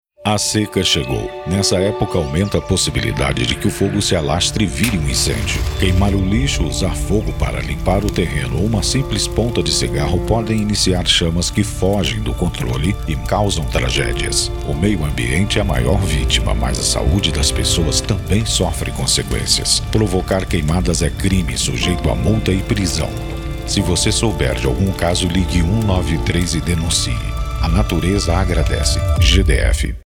SPOT